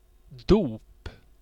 Ääntäminen
US
IPA : /ˈbæptɪzəm/